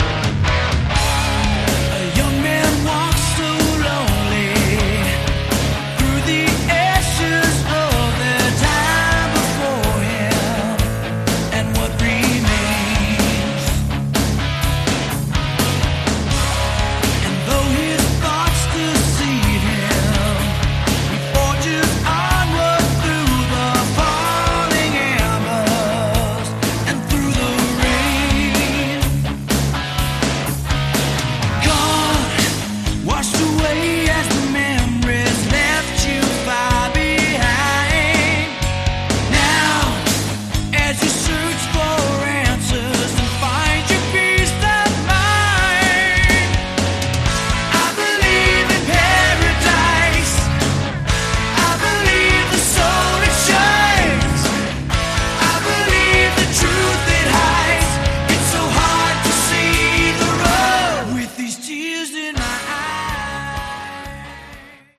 Category: Melodic Metal
guitar, keyboards and vocals
drums and vocals